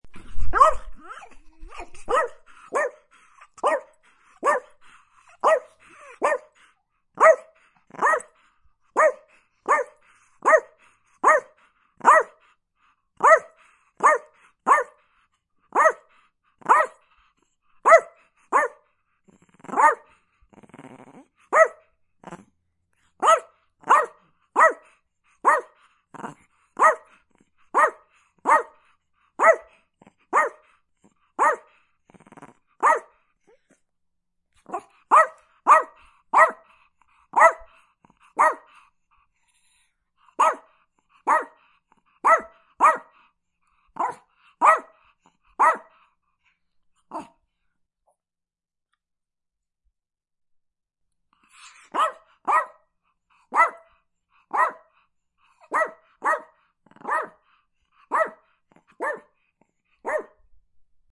Chihuahua Barking Téléchargement d'Effet Sonore